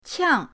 qiang4.mp3